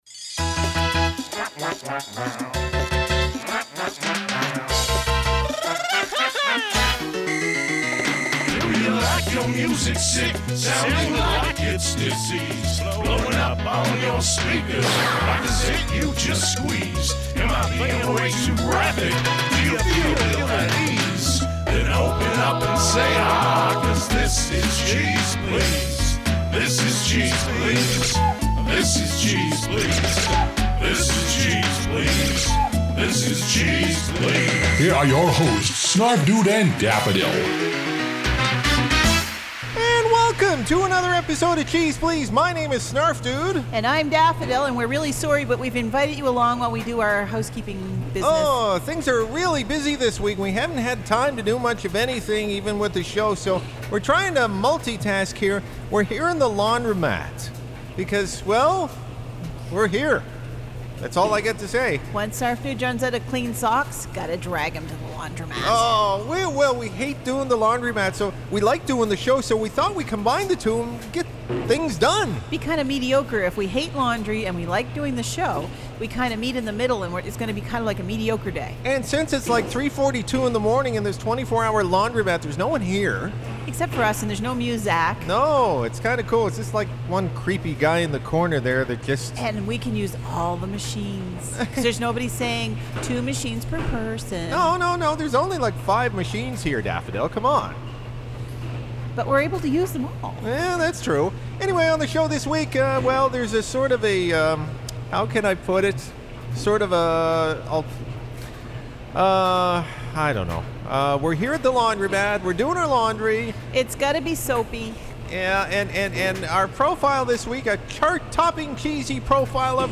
Our hosts multitask this week as they catch up with some laundry and do the show at the laundromat..